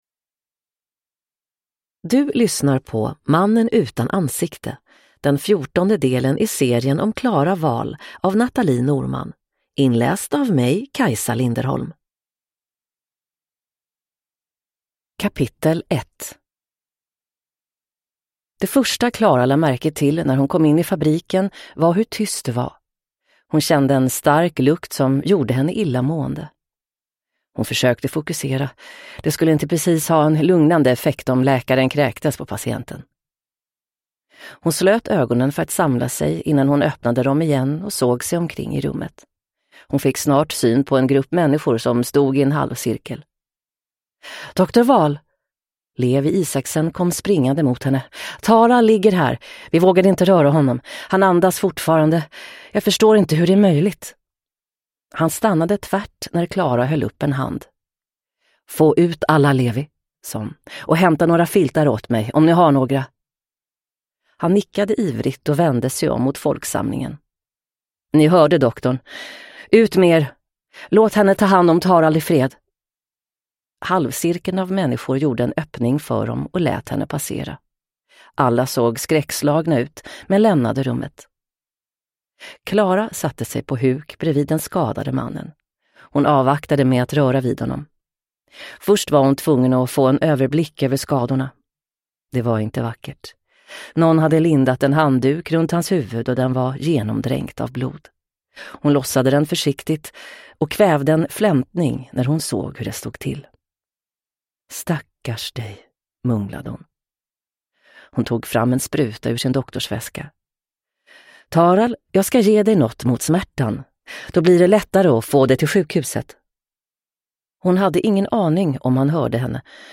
Mannen utan ansikte – Ljudbok